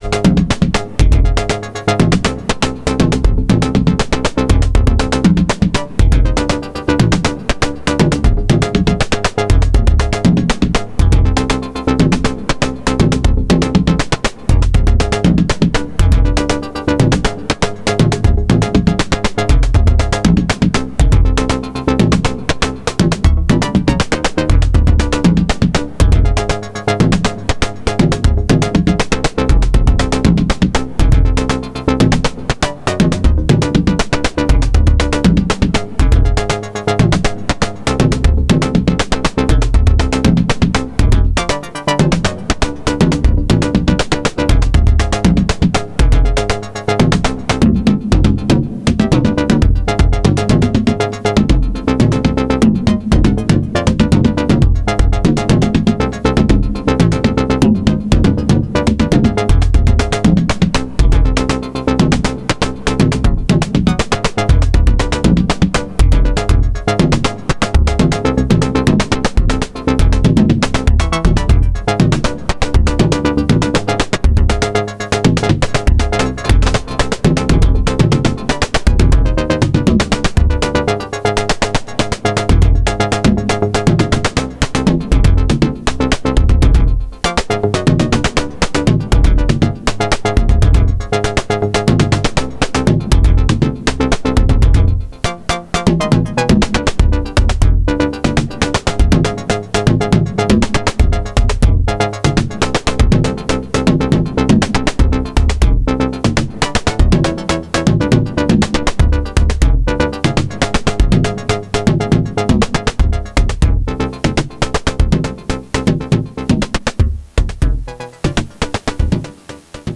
12th Jan 2021 | Experiment
AKAI MFC42 Filter
Dave Smith Instruments Evolver Synthesizer / Digitally Controlled Oscillator
Nord nord drum 3P Synthesizer / Analog Modeling
Roland SP-404SX Sampler / 16bit Digital
Voicing Ants IHo8 music sequencer